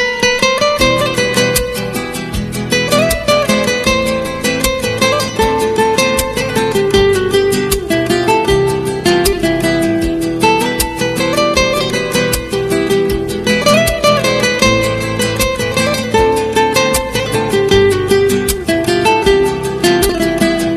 Category: Guitar Ringtones